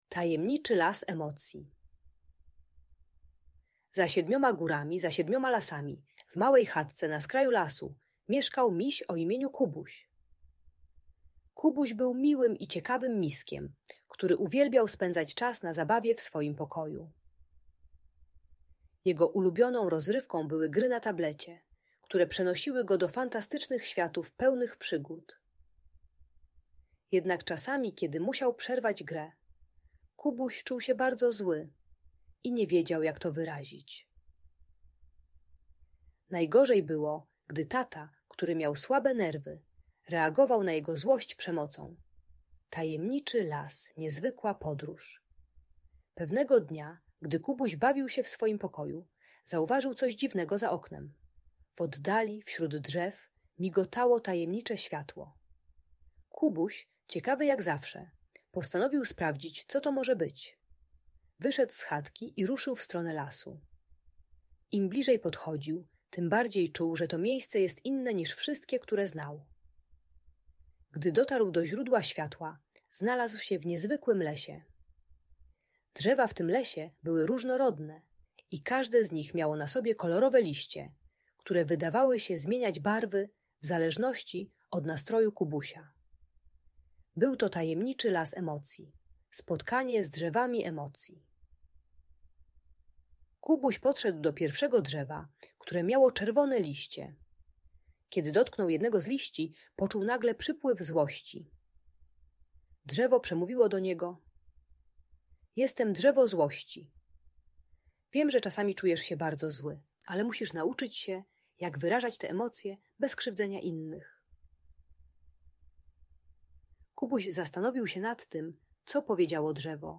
Tajemniczy Las Emocji - historia pełna przygód - Audiobajka